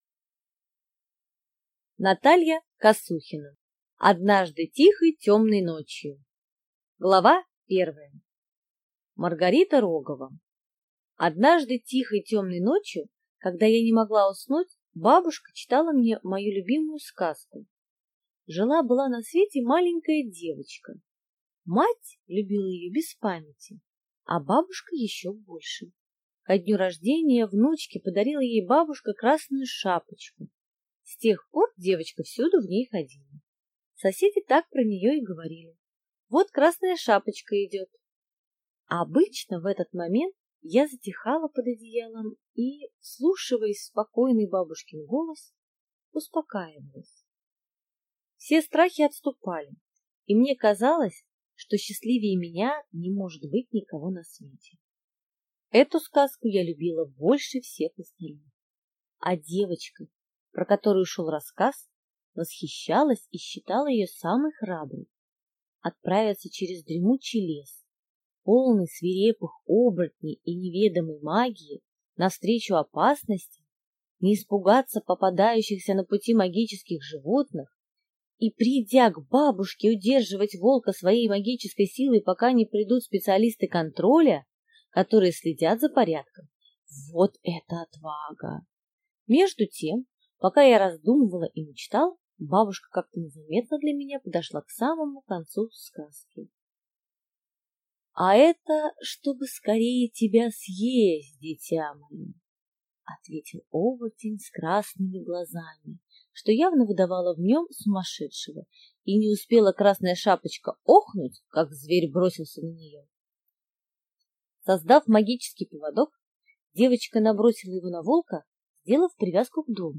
Аудиокнига Однажды тихой темной ночью